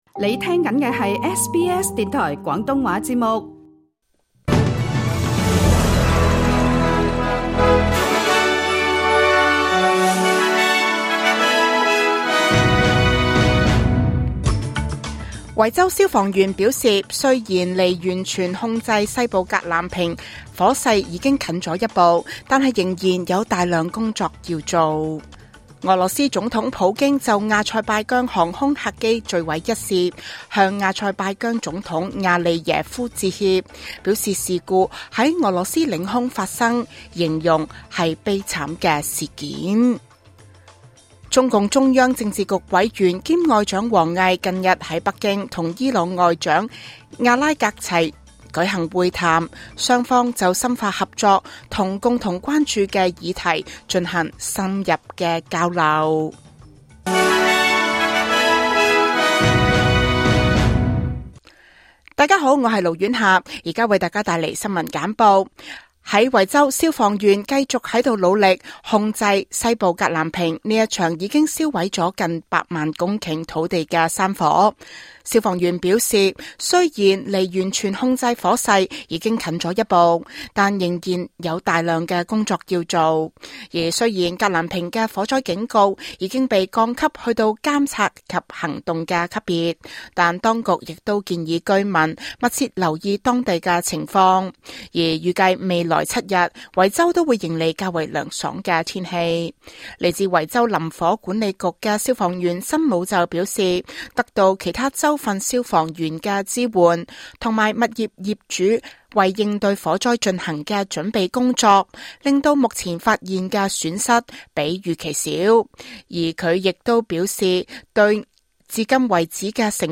2024 年 12 月 28 日 SBS 廣東話節目詳盡早晨新聞報道。